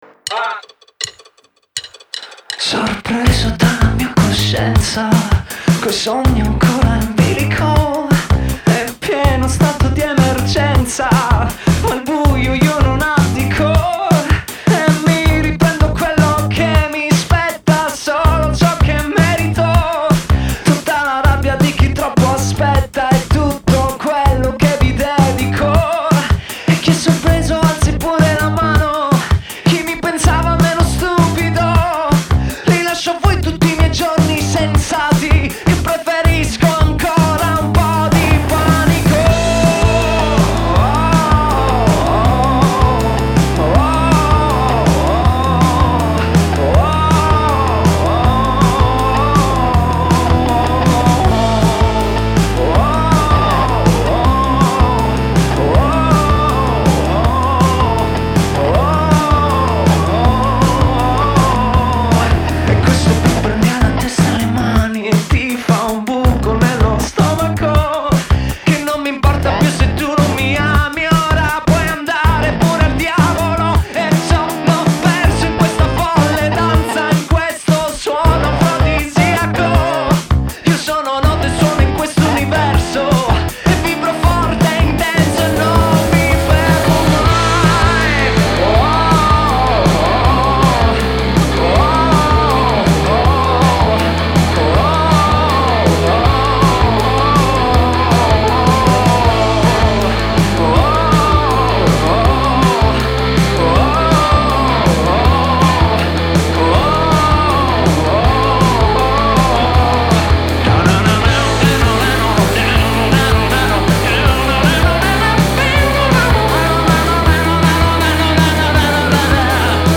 Genre: Pop Rock, Indie, Alternative